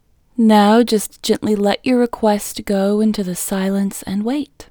LOCATE IN English Female 26